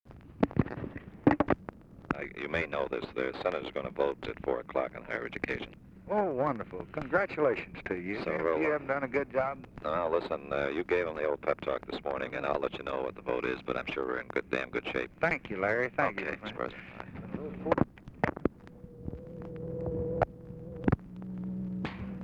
Conversation with LARRY O'BRIEN, December 10, 1963
Secret White House Tapes